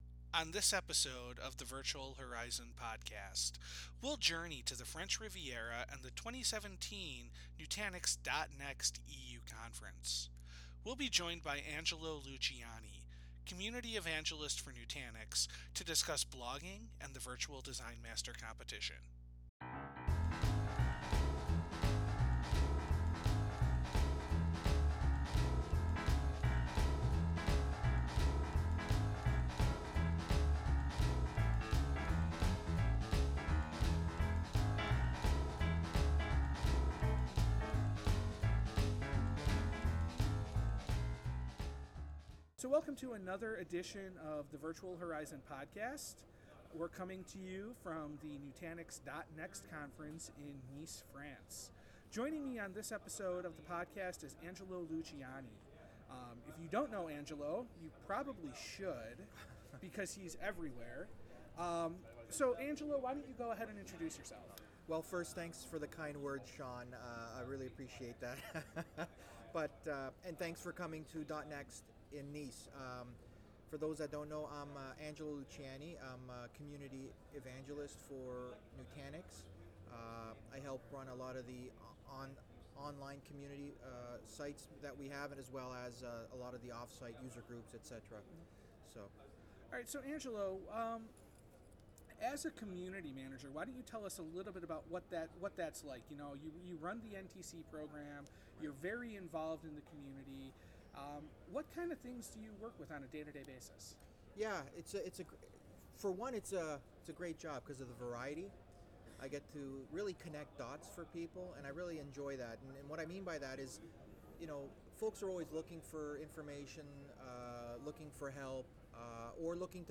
On this episode of The Virtual Horizon podcast, we’ll journey to the French Rivera for the 2017 Nutanix .Next EU conference.